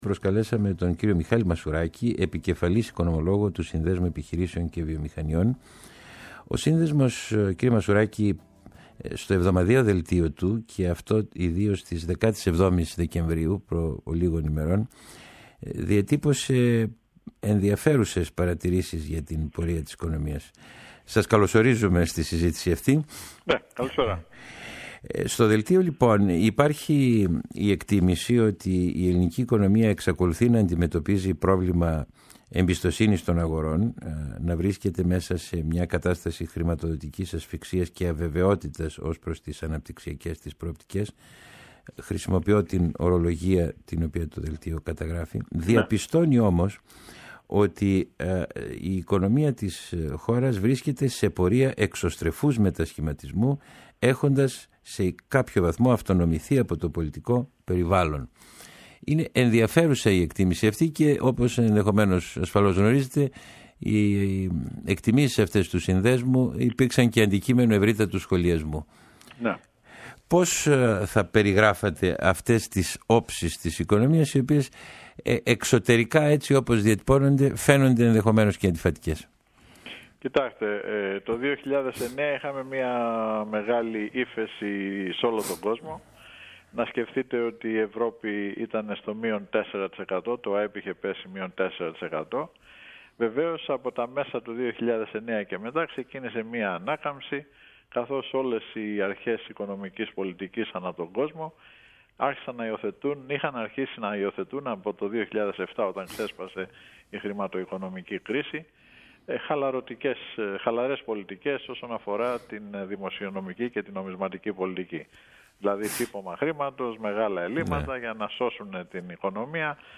στον Ρ/Σ ΕΡΑ 5